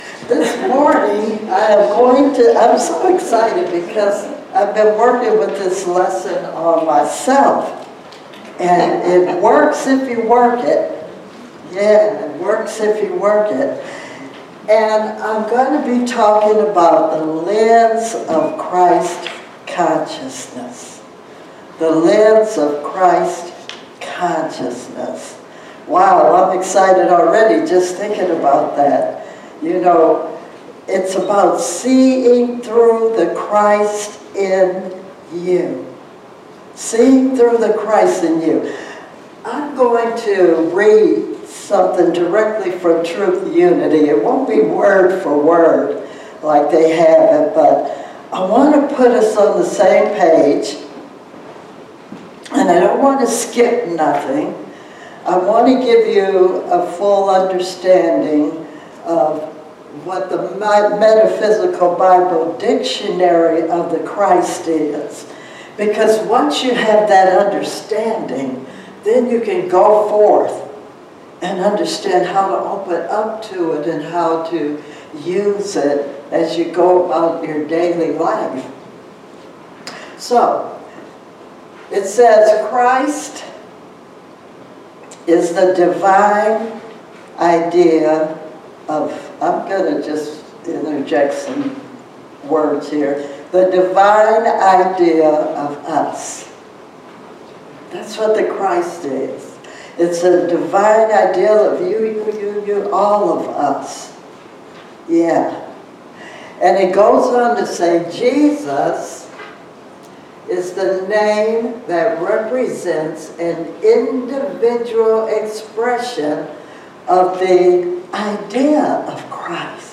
12/21/25 ~ Symbols of the Season: Christmas Candle Lighting Service
Sermons 2025